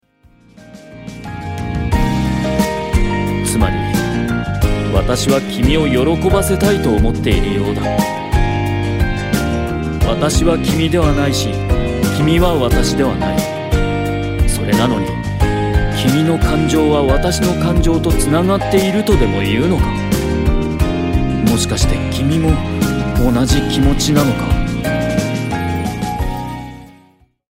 キャラクターソング
語り